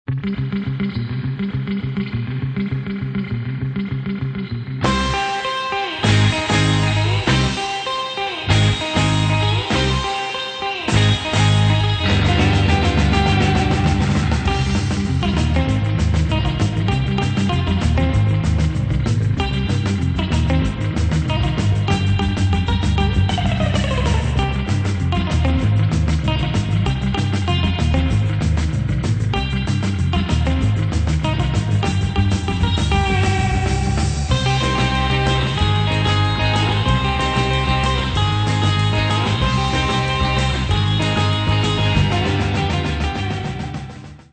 funny medium instr.